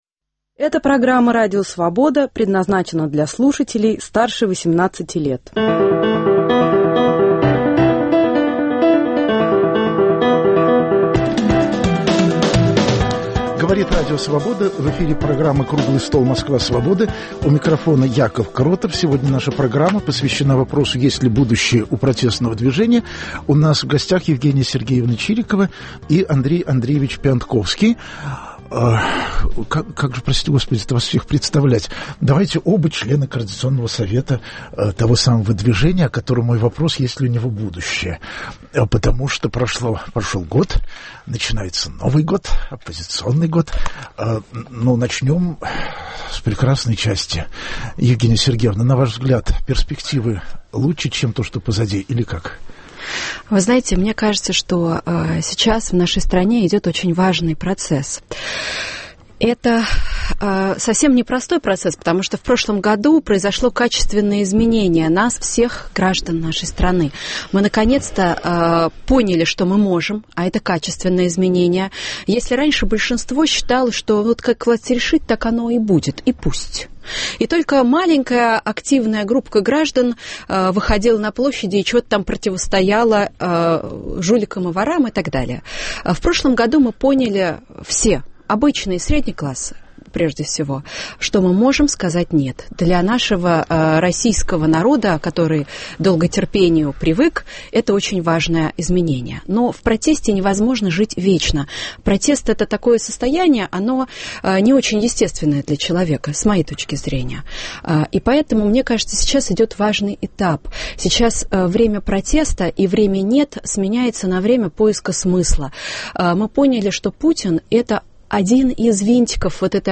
Круглый стол: Москва Свободы
Есть ли будущее у протестного движения в России? Об этом в прямом эфире будут говорить Андрей Пионтковский и Евгения Чирикова.